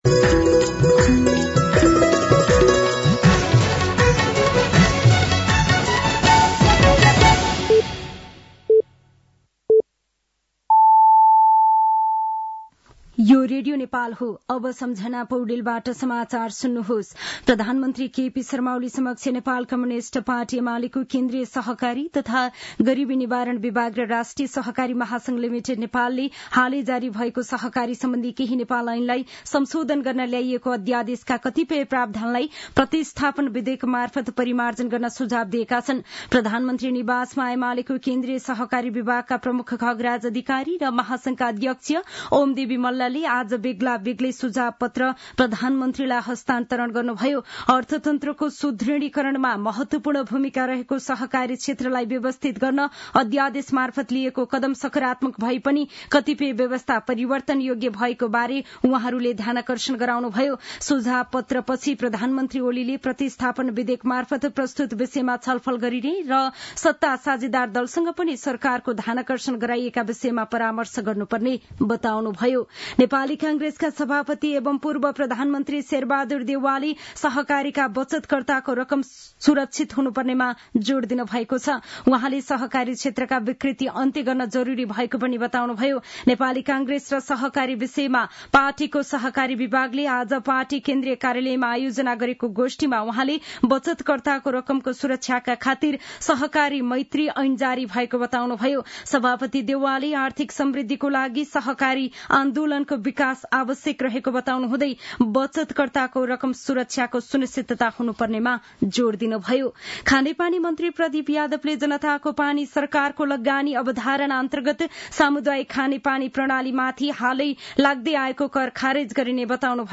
An online outlet of Nepal's national radio broadcaster
साँझ ५ बजेको नेपाली समाचार : २० माघ , २०८१